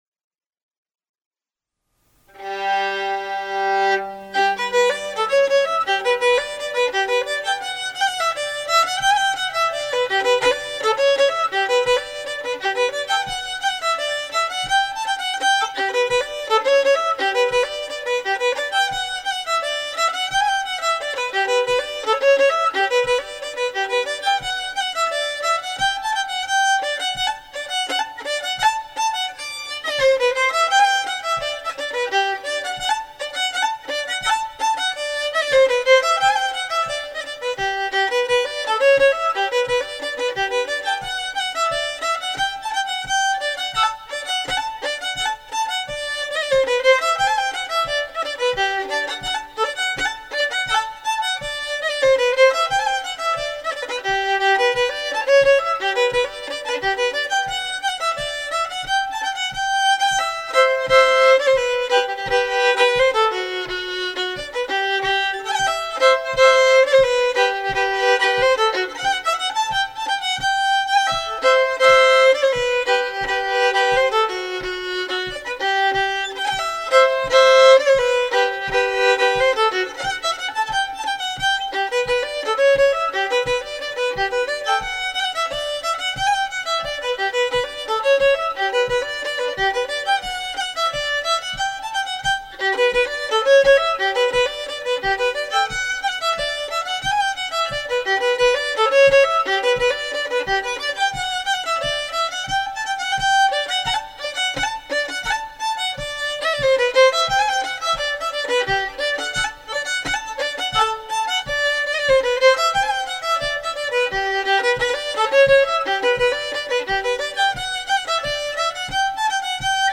Schottis efter Tolv Manne - - (noten) · (
Aber vielleicht war das ja auch notwendig - es ist halt eine wilde Nummer...